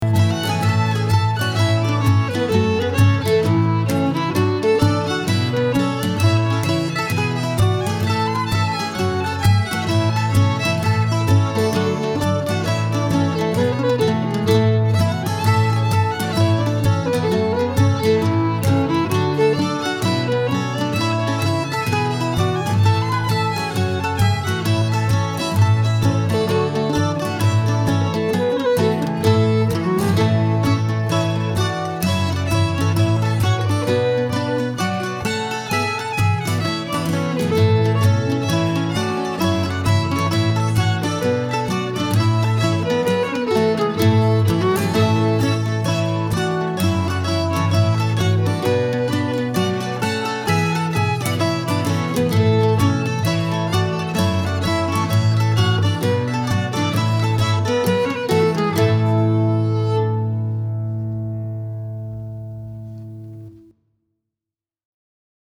Key: A
Form: Hornpipe
Region: England